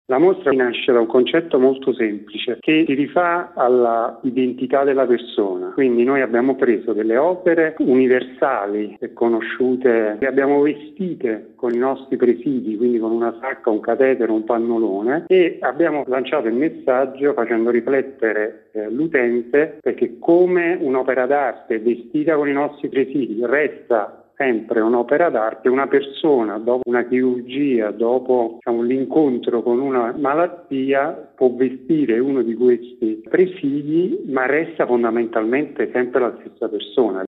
Torna il primo maggio a Roma il consueto concerto promosso da Cgil, Cisl e Uil in occasione della Festa dei Lavoratori. Il servizio